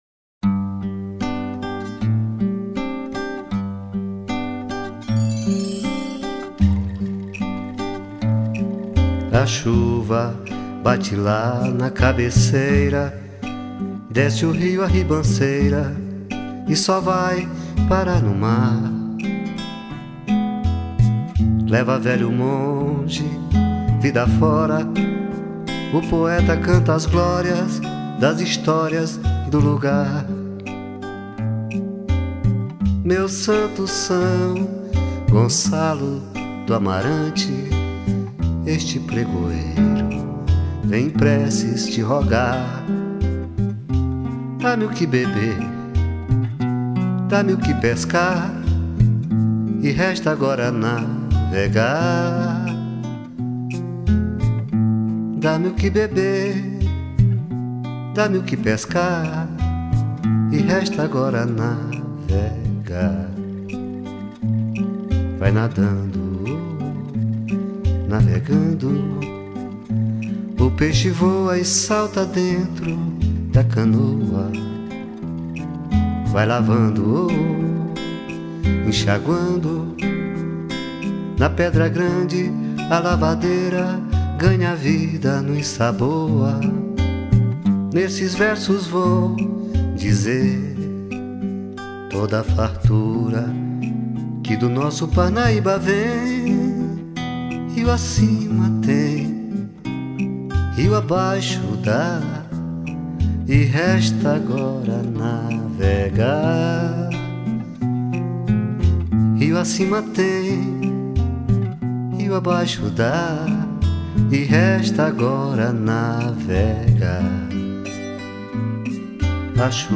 11   03:04:00   Faixa:     Mpb
Violao Acústico 6
Viola de 12 cordas
Percussão